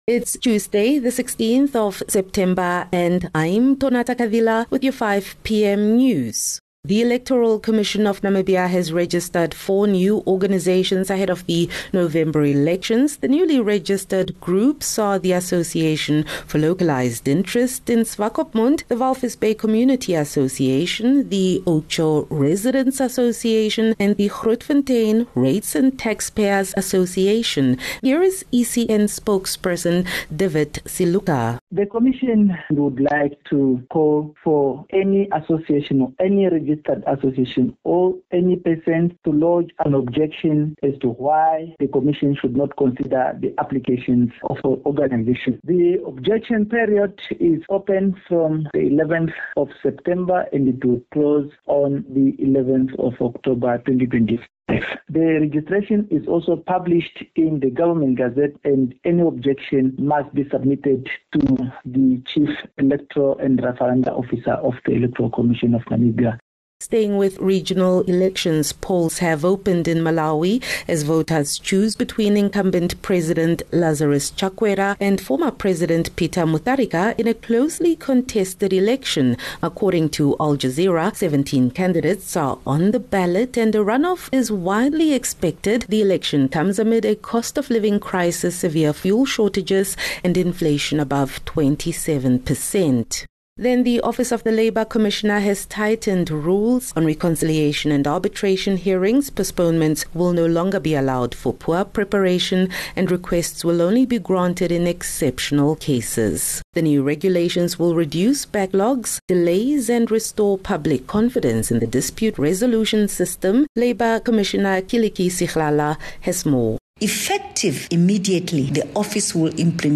16 Sep 16 September - 5 pm news